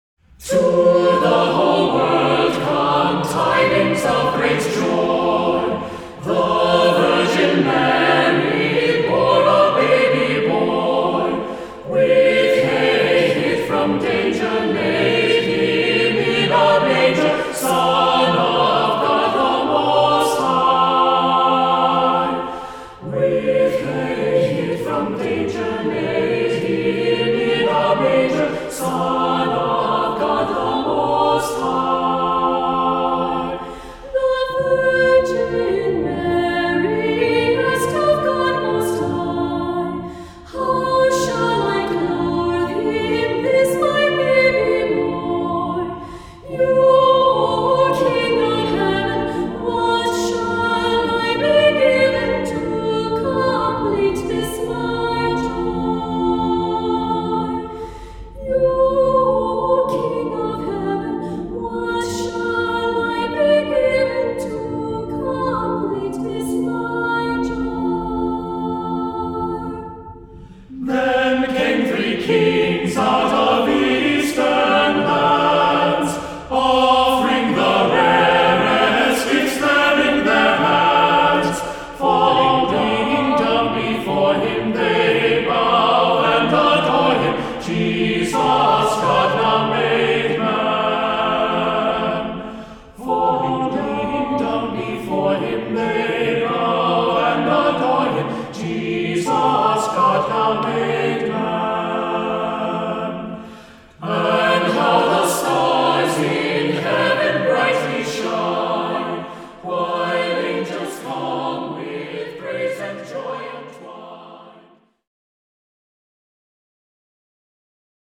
Voicing: a cappella,SATB